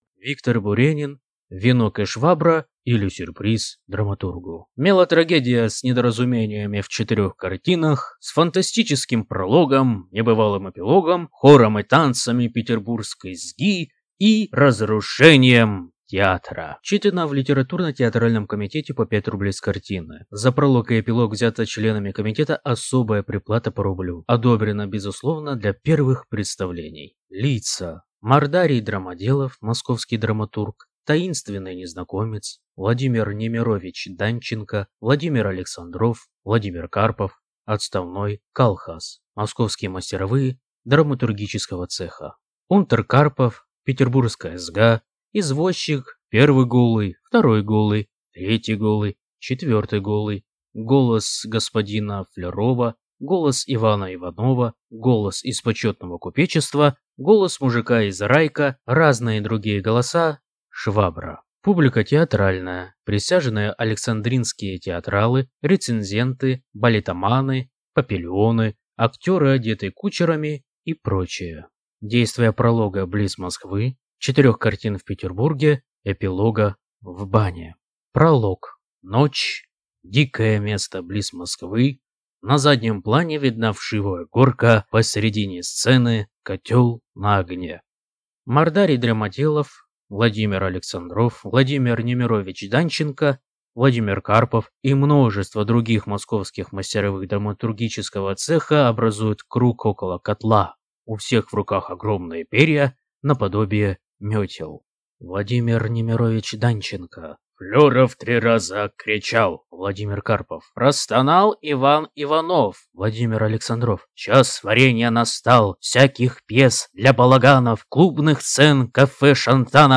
Аудиокнига Венок и швабра, или Сюрприз драматургу | Библиотека аудиокниг